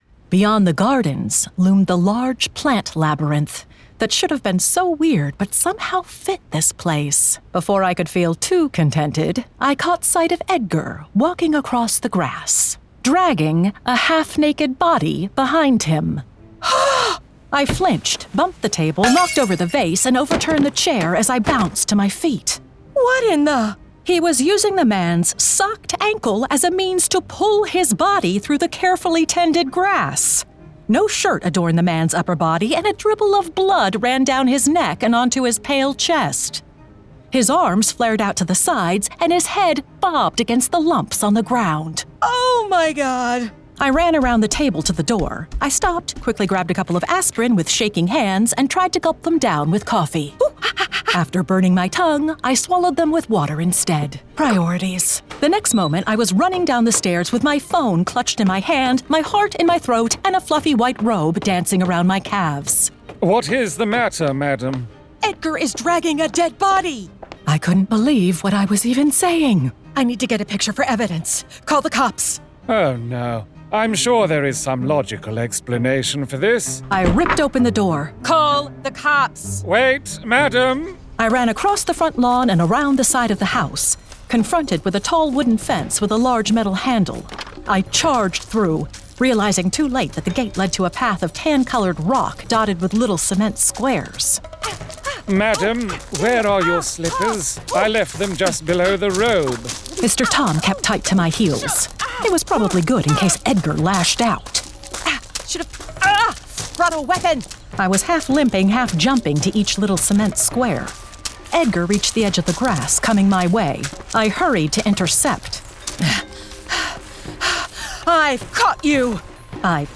Full Cast. Cinematic Music. Sound Effects.
Genre: Fantasy Romance
Adapted from the novel and produced with a full cast of actors, immersive sound effects and cinematic music!